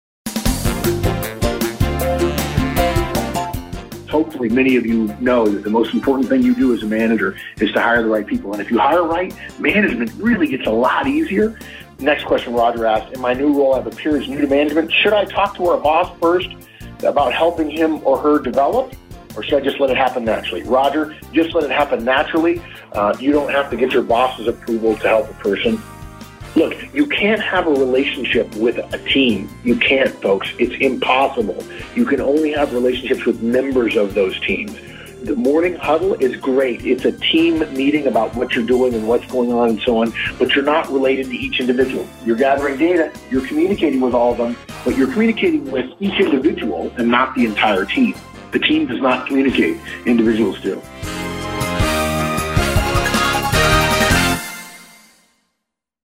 We've recently held two conference calls - the first for our charter members and the second for our premium members.